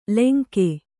♪ lenke